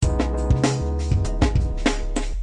Drumloop98bpms